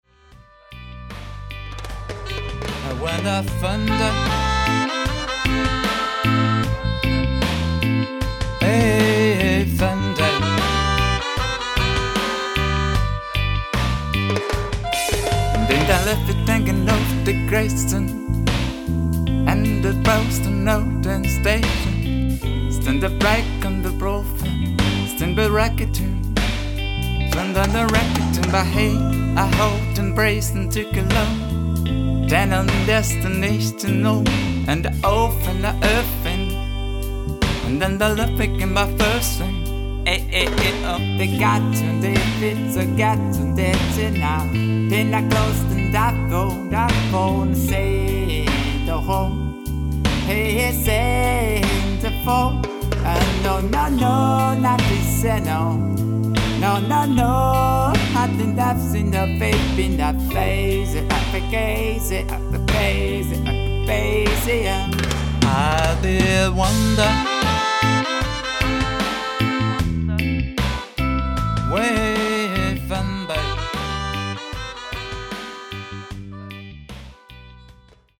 Play Along Songs not only for Drumming